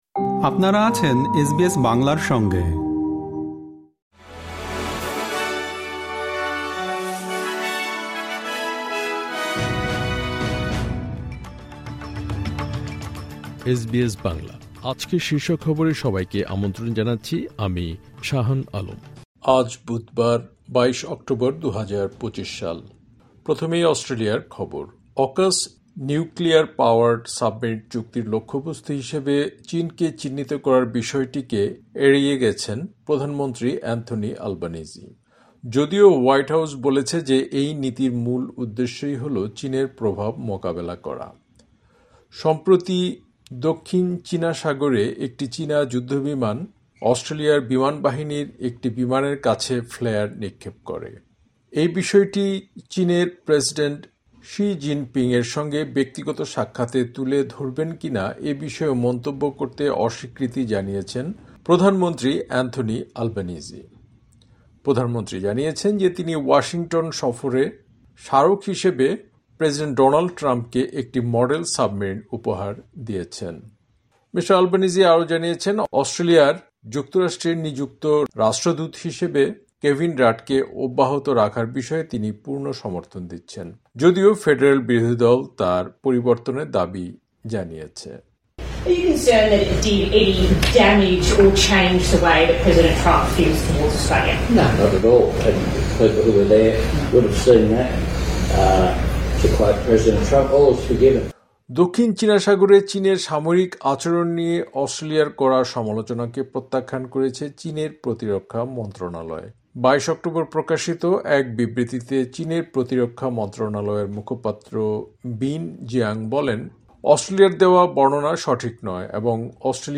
এসবিএস বাংলা শীর্ষ খবর: ২২ অক্টোবর, ২০২৫